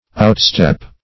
Outstep \Out*step"\